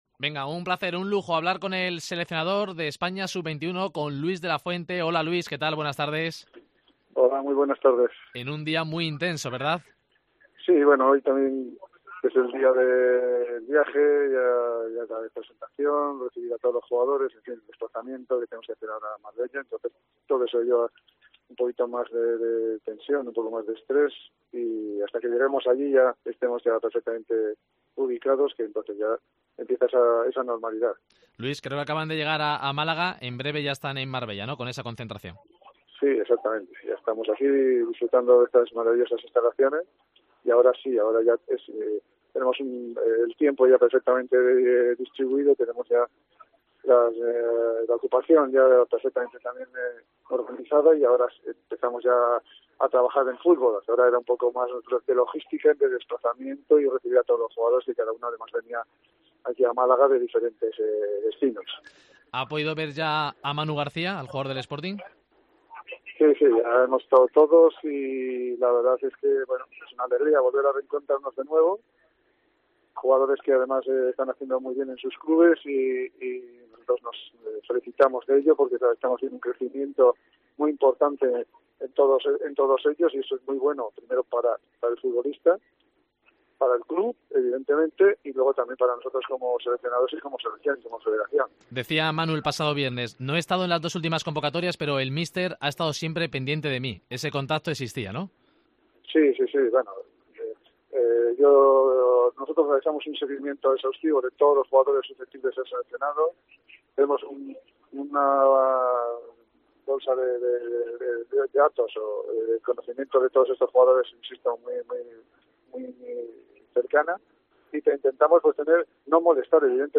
Entrevista a Luis de la Fuente, seleccionador Sub 21